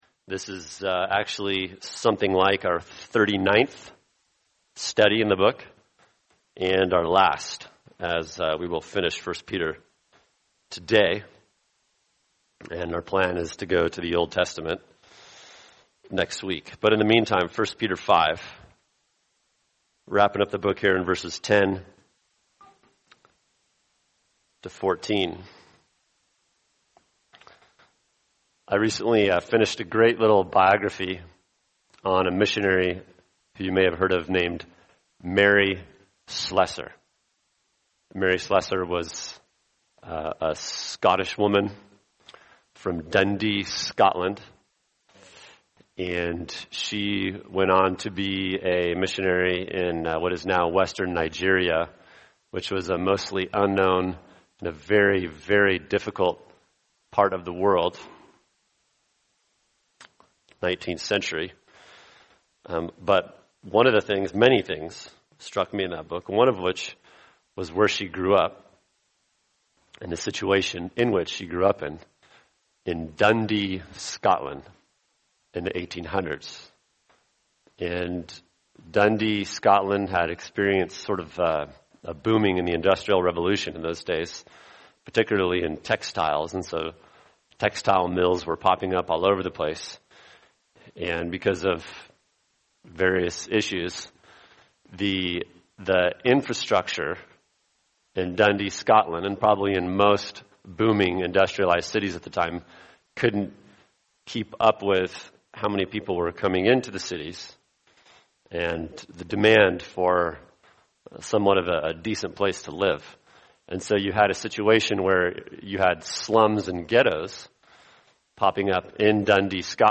[sermon] 1 Peter 5:10-14 Strength to Persevere | Cornerstone Church - Jackson Hole